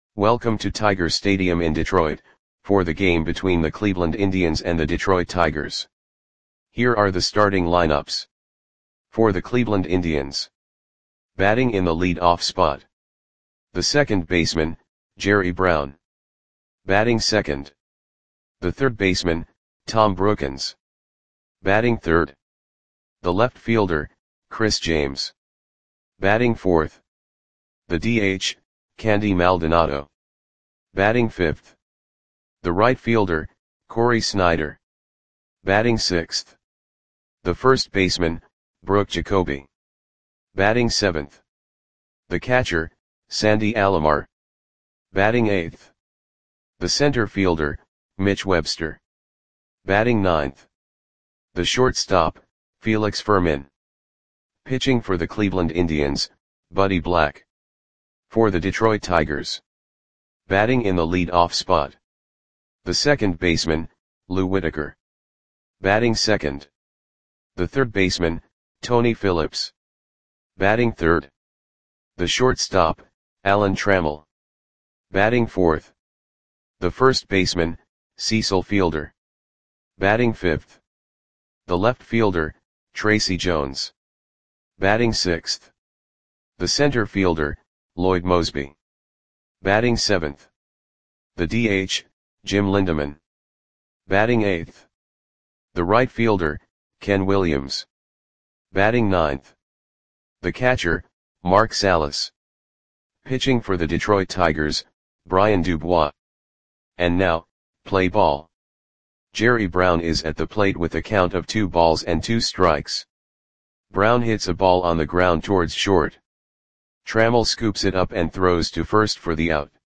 Lineups for the Detroit Tigers versus Cleveland Indians baseball game on June 12, 1990 at Tiger Stadium (Detroit, MI).
Click the button below to listen to the audio play-by-play.